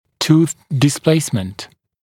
[tuːθ dɪs’pleɪsmənt][ту:с дис’плэйсмэнт]смещенность зубов, атипичное положение зубов